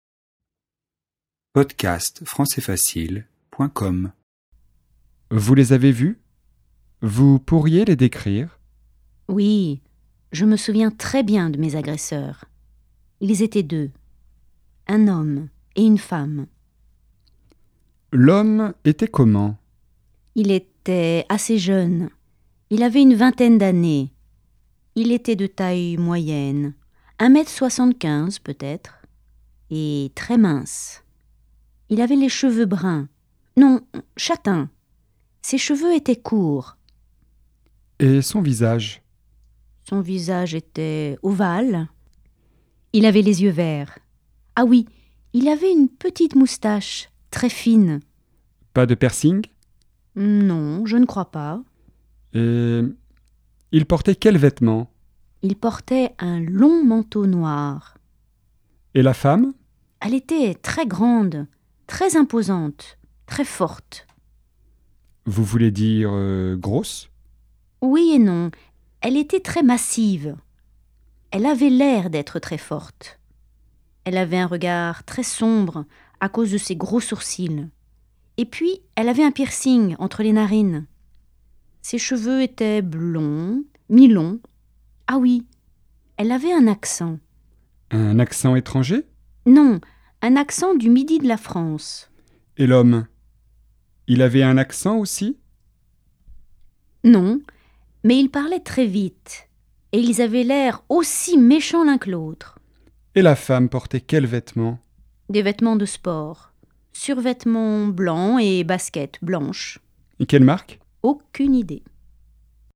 • Registre : soutenu
🔷 DIALOGUE :